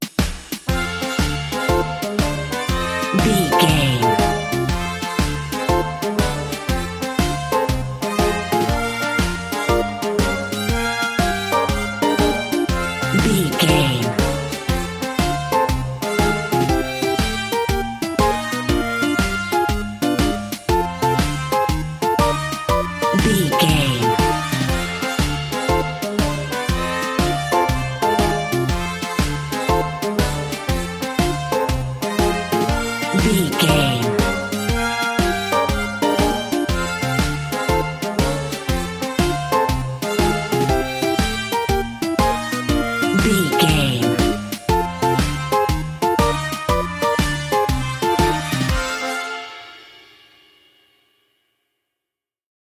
Slow
playful
smooth
bassoon
brass
drums
electric piano
trumpet
bouncy
groovy
funky